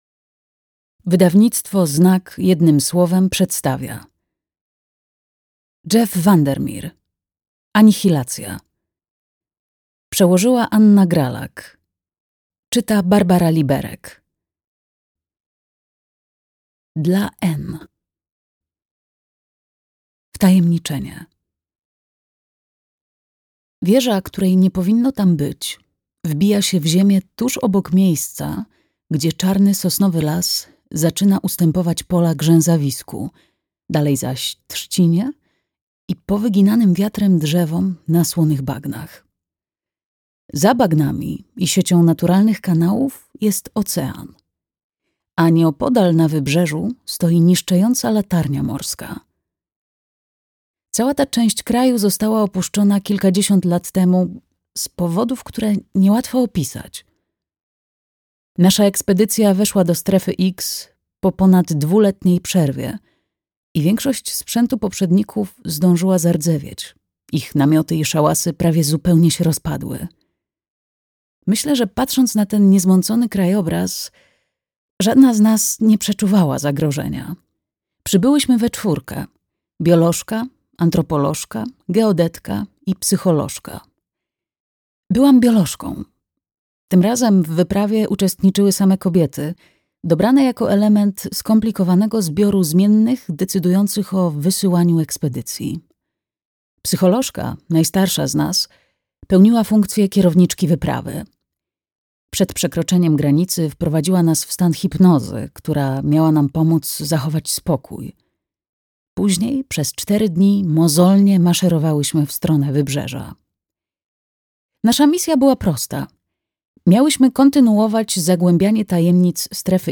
Anihilacja - Jeff VanderMeer - audiobook